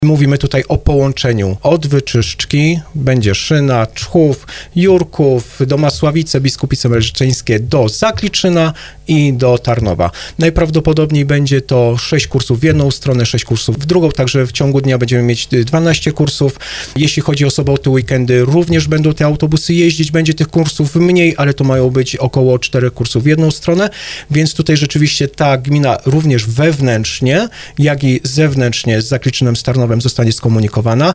Burmistrz Czchowa Krzysztof Szot mówił w programie Słowo za Słowo, że gmina jest teraz w trakcie negocjacji dotyczących szczegółów umowy.